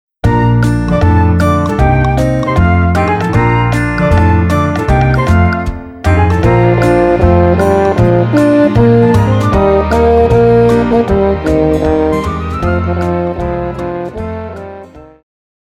Pop
French Horn
Band
Instrumental
Ballad
Only backing